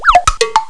Sons de humor 47 sons
whizpopb.wav